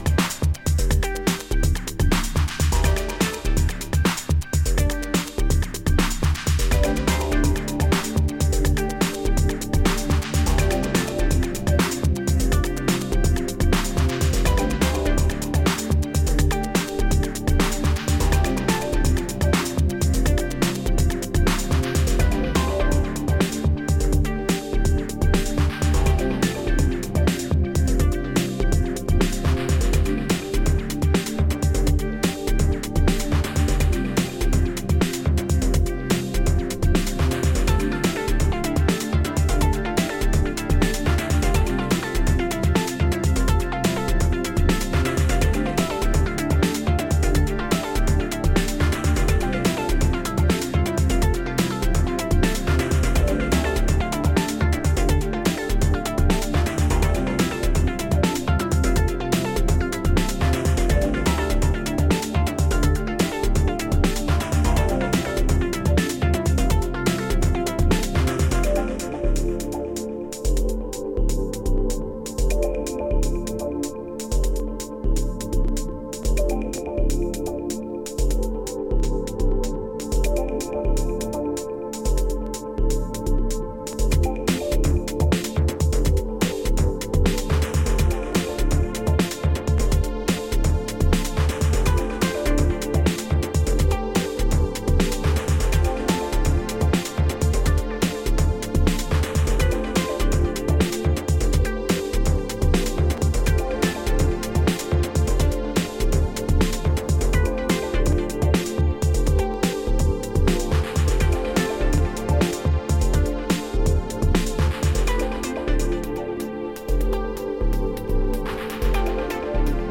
エレクトロニカ/テクノ・ユニット